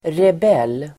Ladda ner uttalet
Uttal: [reb'el:]